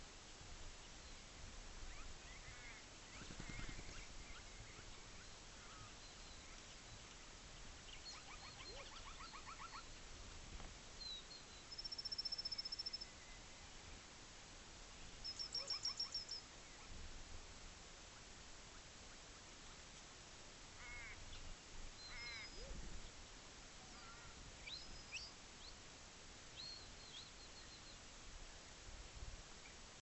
Sturt NP, NSW June 2012
Does anyone have an idea of what this might be (the ‘tinkling’ call under the arrow)?
There are Little Crow, Yellow-throated Miner and probably Crimson Chat on this
The sound is I think just that of a Wren, but unsure of the exact species